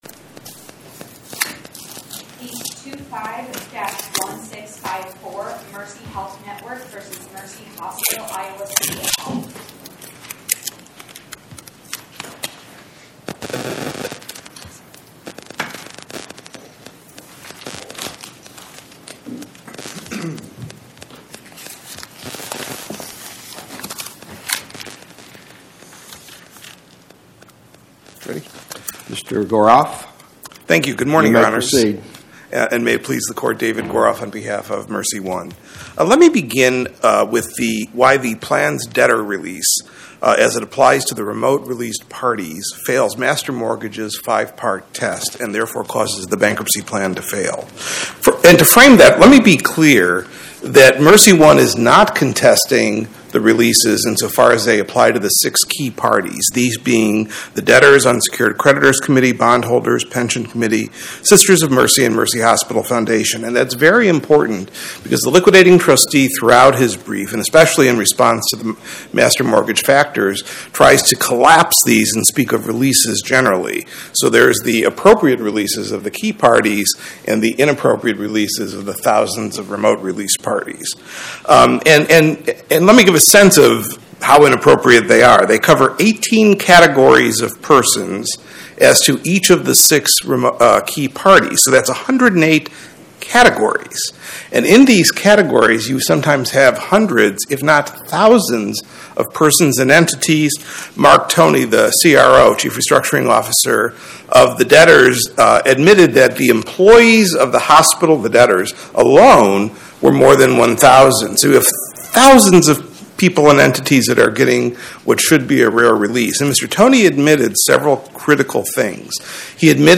My Sentiment & Notes 25-1654: Mercy Health Network vs Mercy Hospital, Iowa City, IA Podcast: Oral Arguments from the Eighth Circuit U.S. Court of Appeals Published On: Thu Jan 15 2026 Description: Oral argument argued before the Eighth Circuit U.S. Court of Appeals on or about 01/15/2026